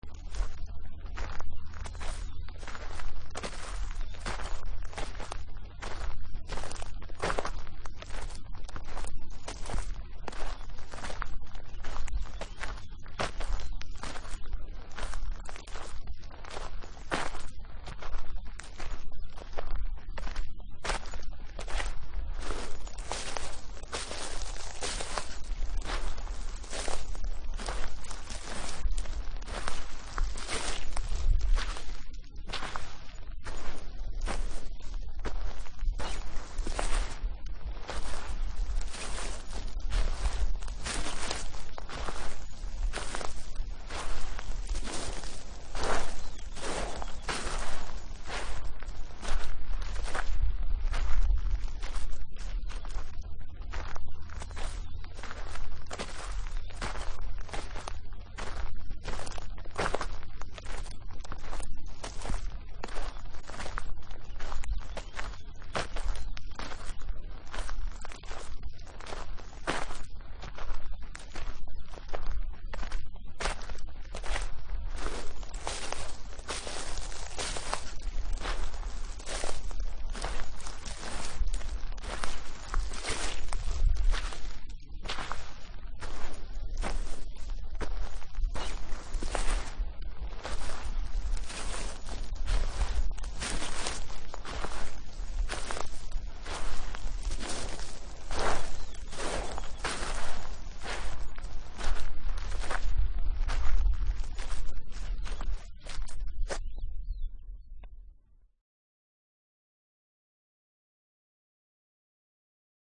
ANDANDO SOBRE ZONA SECA Y BLANDA
Ambient sound effects
andando_sobre_zona_seca_y_blanda.mp3